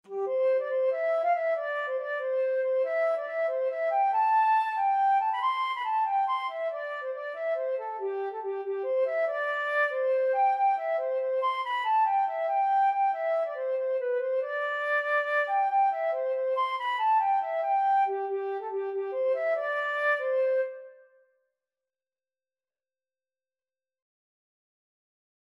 Traditional Trad. Girls of Our Town., The (Irish Folk Song) Flute version
C major (Sounding Pitch) (View more C major Music for Flute )
6/8 (View more 6/8 Music)
G5-C7
Instrument:
Traditional (View more Traditional Flute Music)